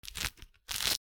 みかんの皮をむく
『ミシ』